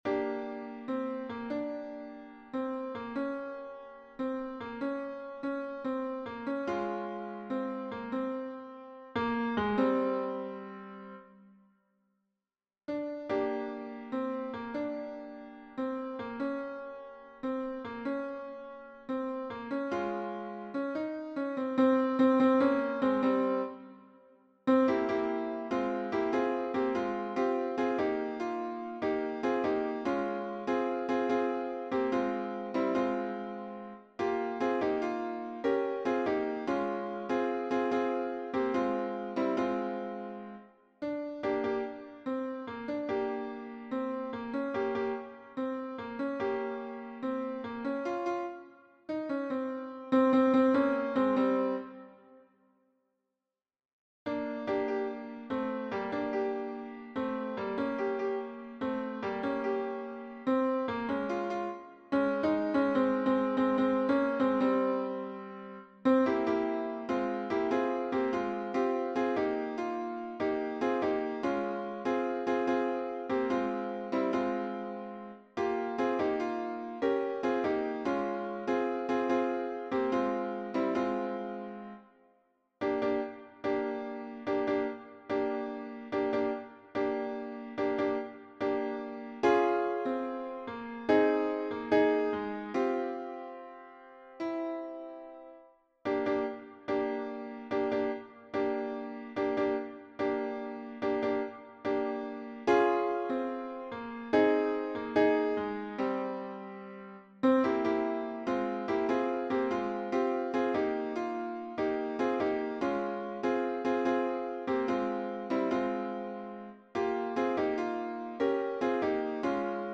MP3 version piano
Tutti